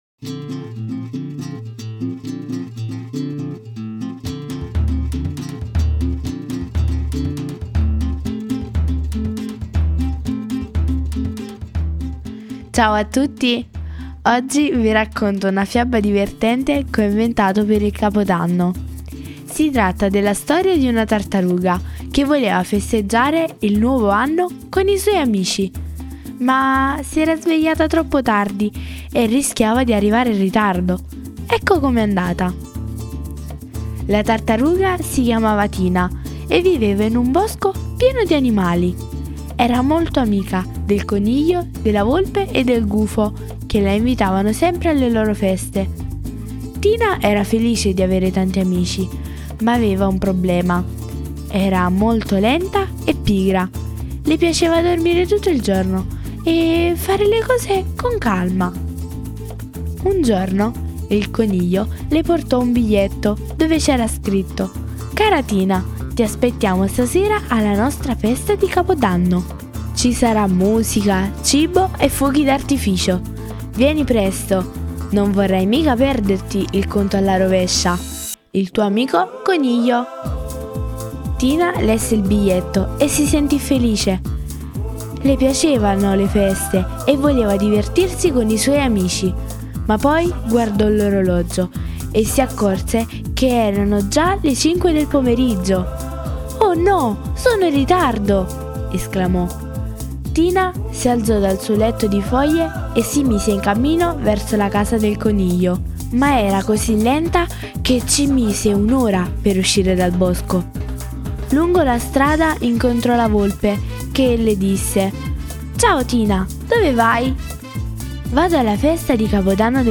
mamma legge la fiaba
Le favole della buonanotte